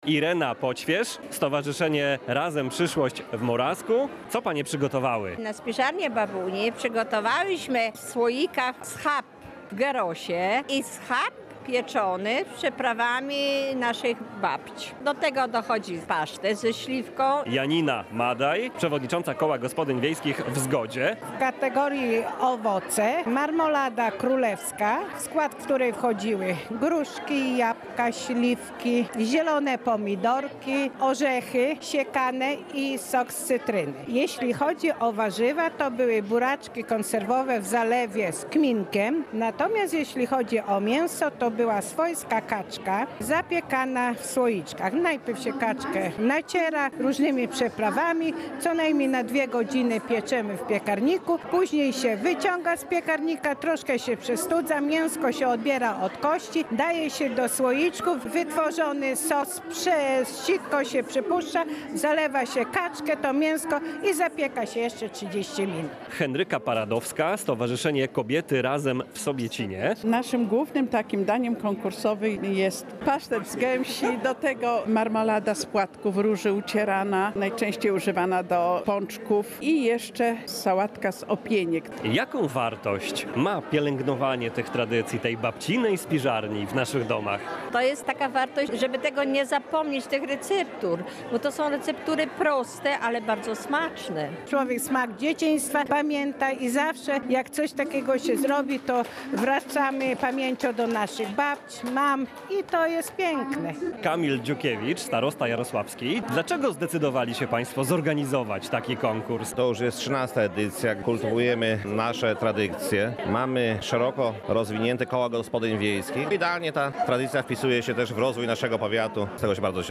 Owoce, warzywa i mięsa lub ryby przyrządzone według tradycyjnych receptur zaprezentowano w Rokietnicy w powiecie jarosławskim podczas konkursu kulinarnego „Z babcinej spiżarni”. 18 kół gospodyń wiejskich przygotowało potrawy, które mają promować lokalne i zdrowe produkty.
Relacja